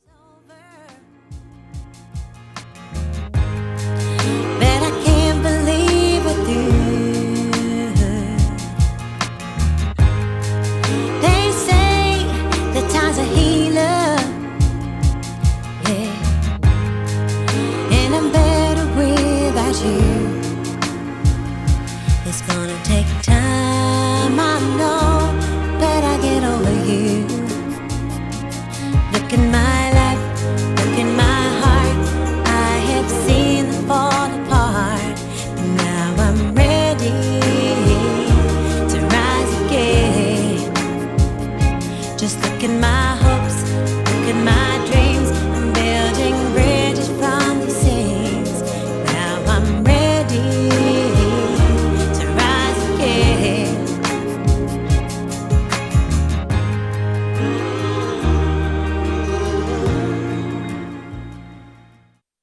Styl: Progressive, House, Breaks/Breakbeat, Trance